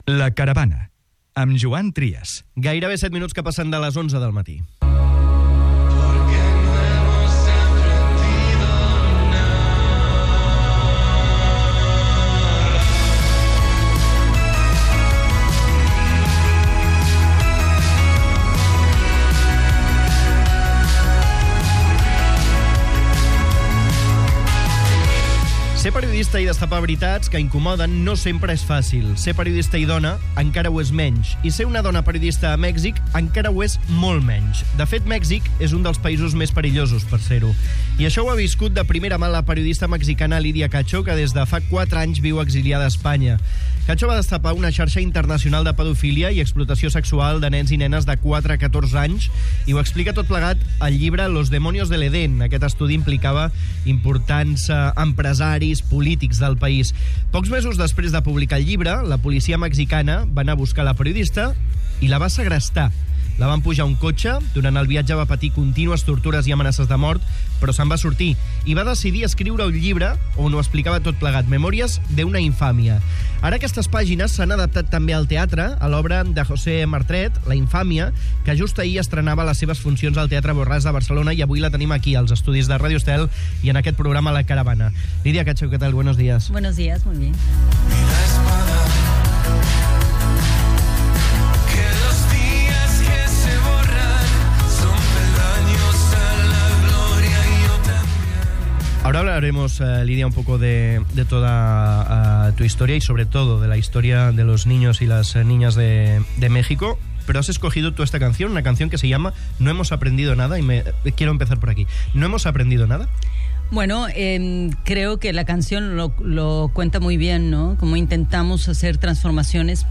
La periodista Lydia Cacho, que inspira l'obra 'La Infamia' al Teatre Borràs, als estudis de Ràdio Estel / Ràdio Estel Ser periodista i destapar veritats que incomoden no és fàcil.